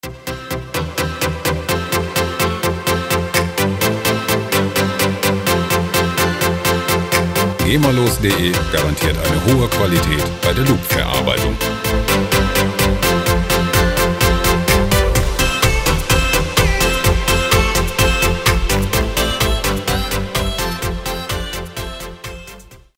gemafreie Musik Loops in der Rubrik "Karaoke"
Musikstil: Pop
Tempo: 127 bpm